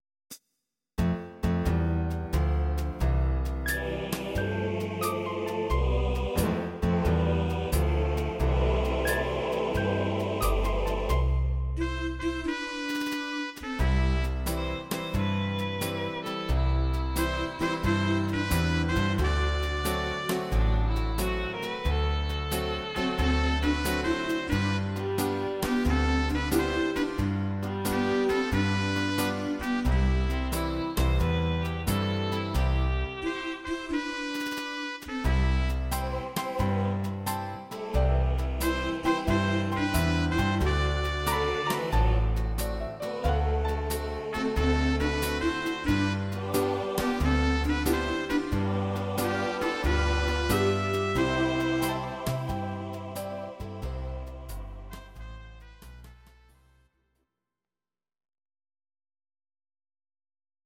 Audio Recordings based on Midi-files
Oldies, Country, Duets, 1960s